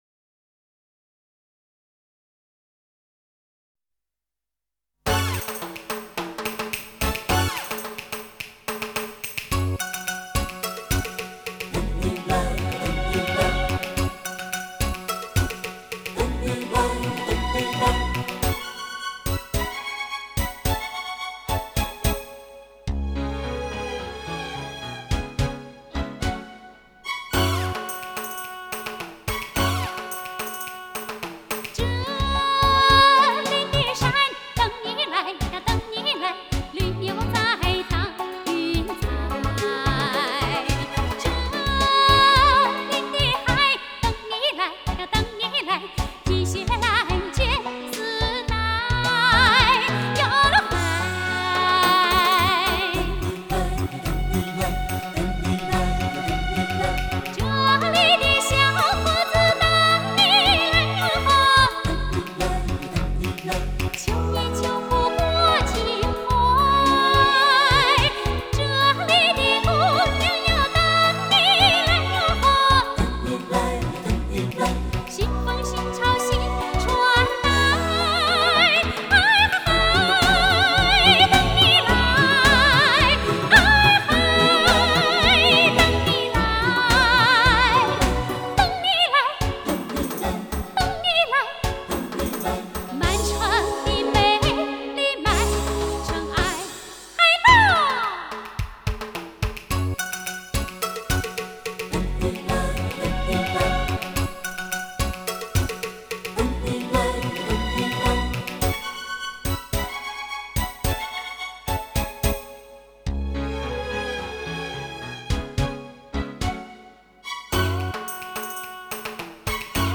Жанр: Chinese pop / Chinese folk